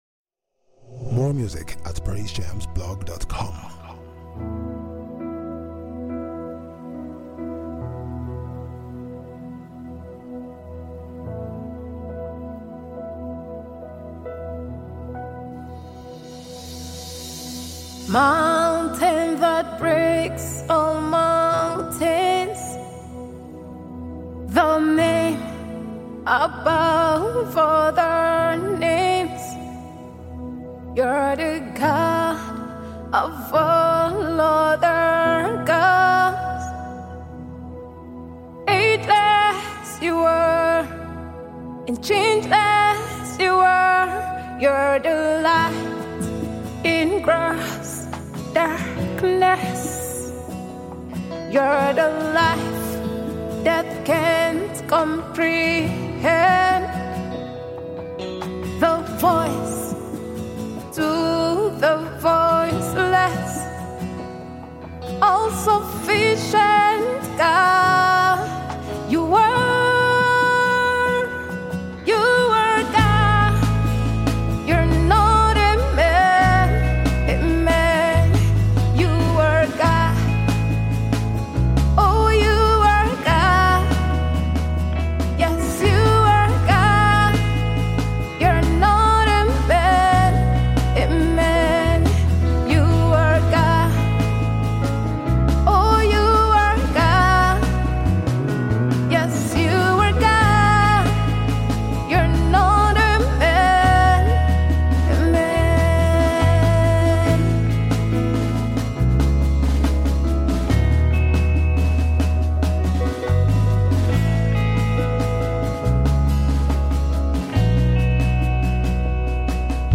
Nigerian Christian recording artist and exquisite songwriter